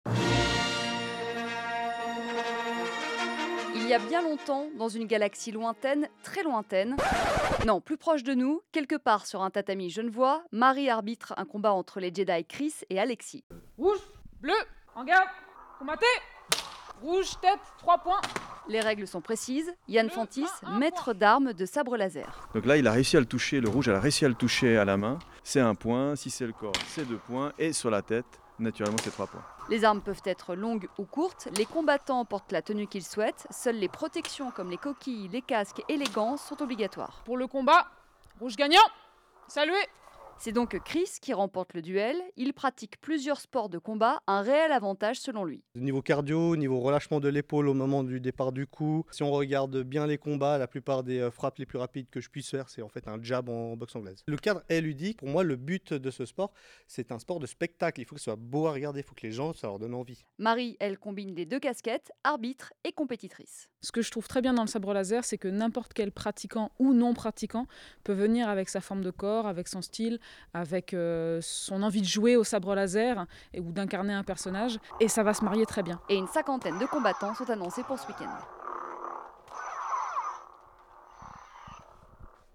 Reportage lors d'un entrainement: